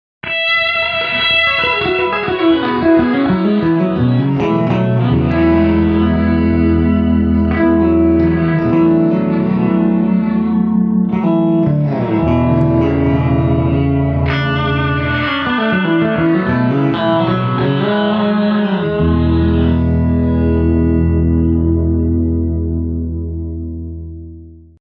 4. REVRS – emulates the quirky reverse reverb effect where a note's reverb fades-in backwards.
Reverse Reverb
Oceans-11Reverb-Reverse-Reverb.mp3